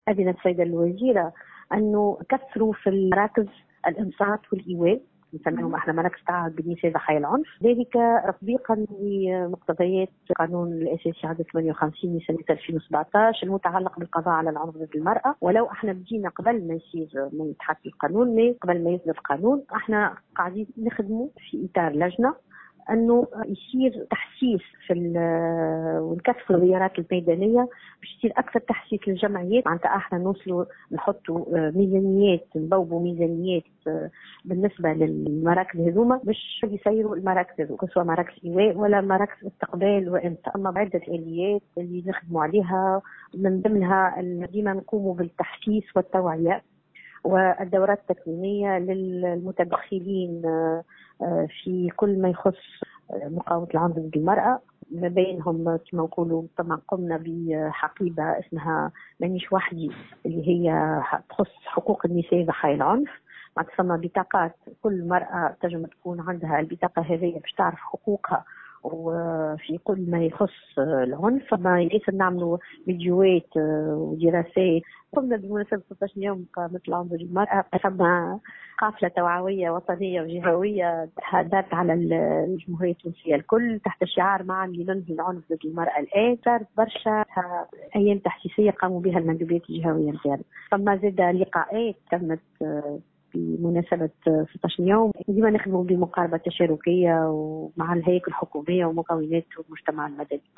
s’est exprimée aujourd’hui au micro de Tunisie Numérique